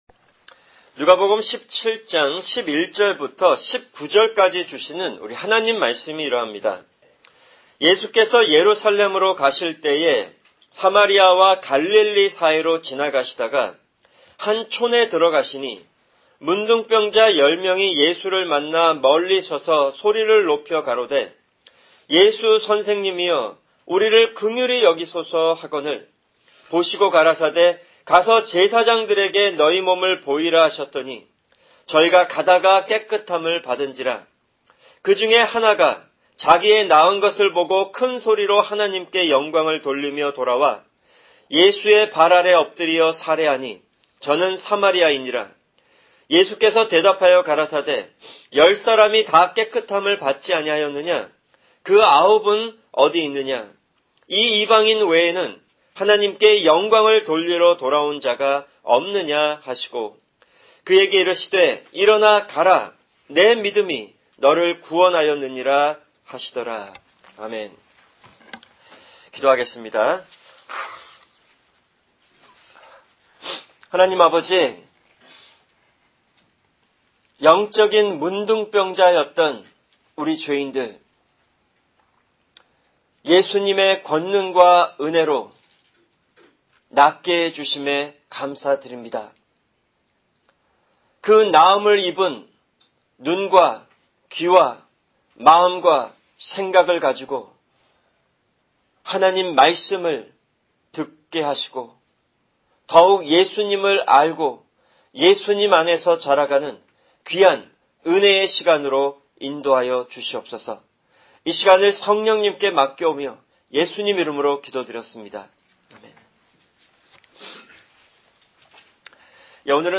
[금요 성경공부] 민수기(49) 36:1-13